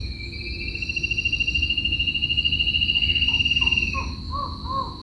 Chocão-carijó (Hypoedaleus guttatus)
Nome em Inglês: Spot-backed Antshrike
Localidade ou área protegida: Santa Ana
Condição: Selvagem
Certeza: Fotografado, Gravado Vocal